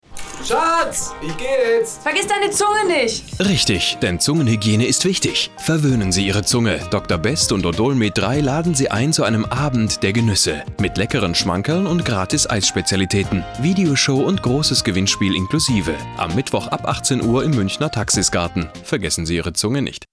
deutscher Sprecher
Sprechprobe: eLearning (Muttersprache):
german male voice over